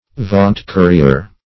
Vaunt-courier \Vaunt"-cou`ri*er\, n.